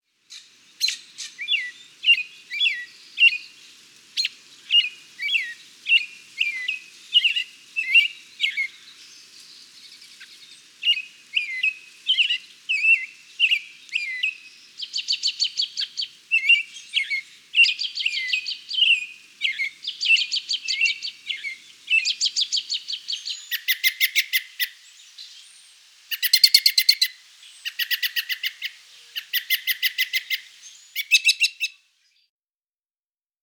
Robin
12-american-robin1.m4a